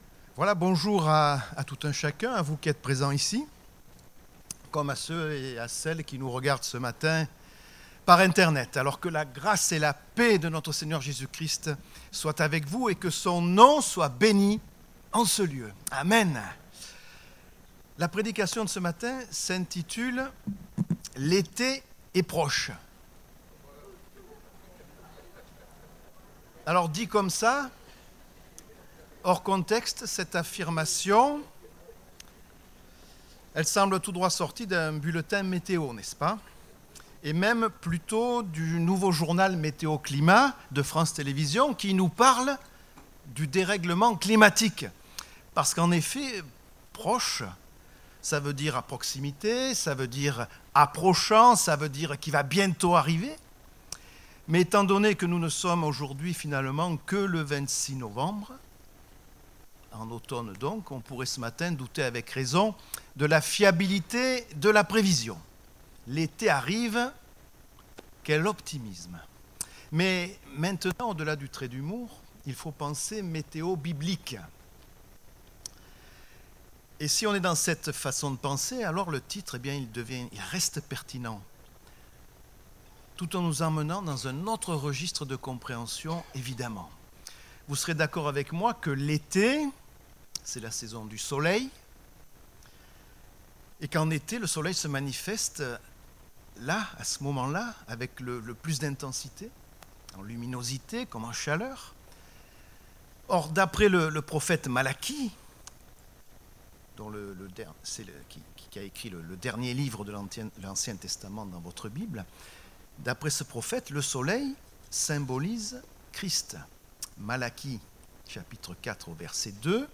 Date : 26 novembre 2023 (Culte Dominical)